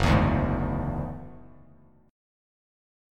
Gb7sus2#5 chord